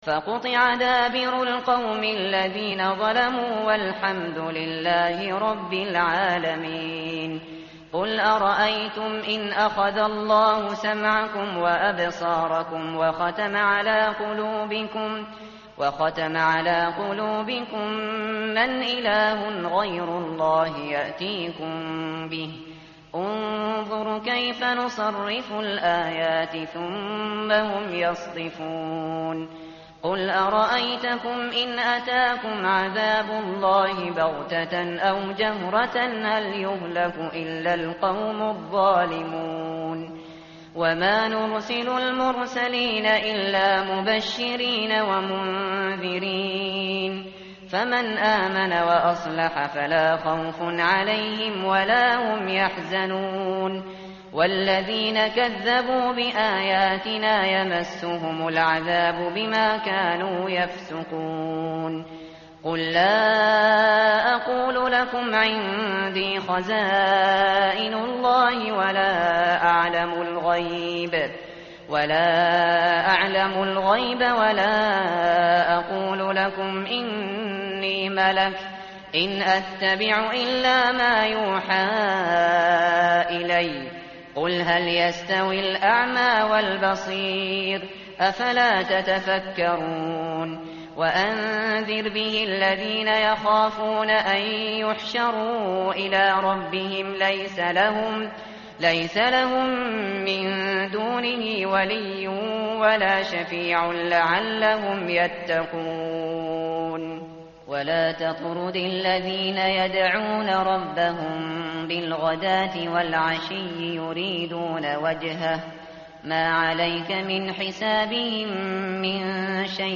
متن قرآن همراه باتلاوت قرآن و ترجمه
tartil_shateri_page_133.mp3